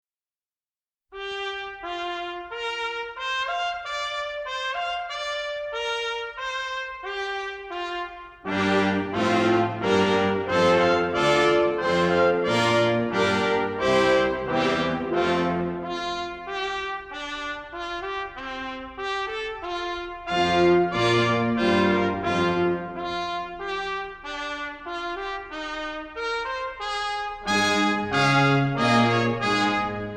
パーパーパー♪で「展覧会の絵より プロムナード」ムソルグスキー Mussorgsky Pictures at an Exhibition - Promenade - チャララー♪でクラシック音楽の一部を表現してみました。